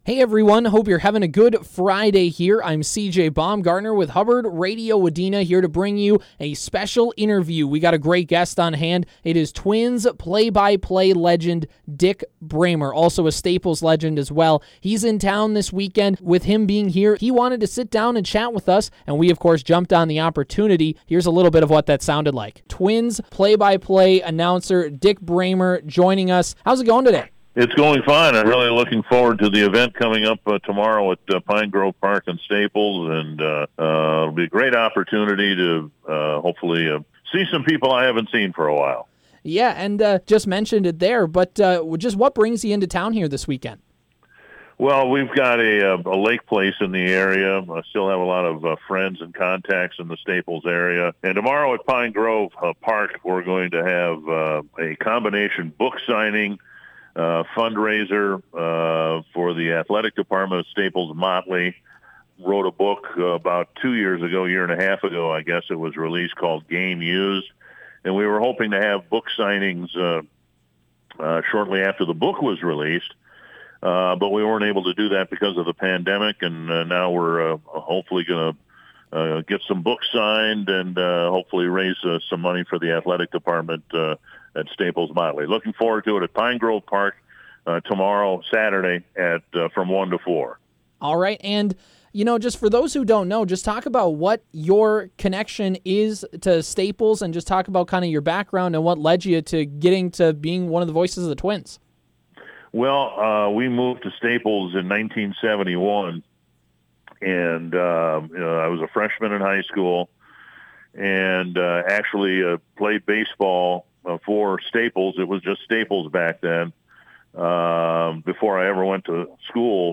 Special Interview: Twins Announcer Dick Bremer Talks Return to Staples, Twins Stories and More – Superstation K-106
BREMER-INTERVIEW-WEB.mp3